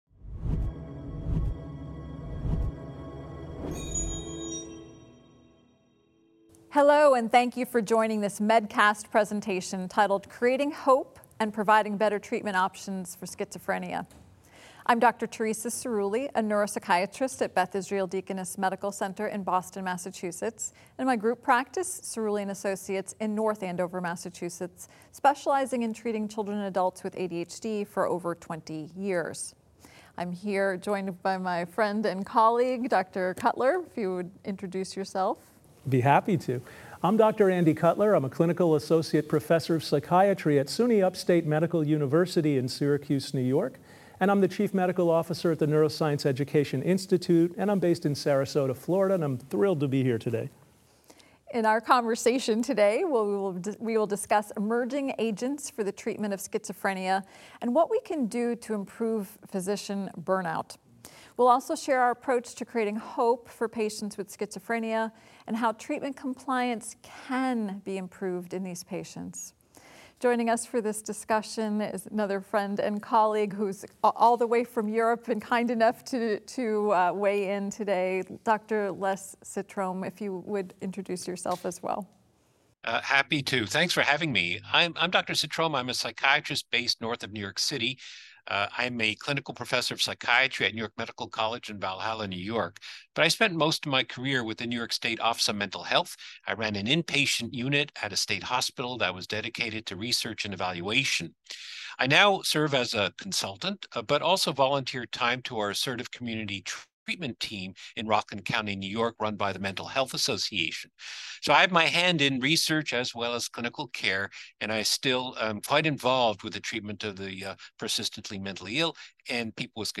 In this episode of MEDcast, the expert panelists discuss emerging agents for treatment of schizophrenia and what we can be done to improve physician burnout. They also share approaches to creating hope for patients with schizophrenia and to improving treatment compliance.